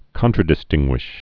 (kŏntrə-dĭ-stĭnggwĭsh)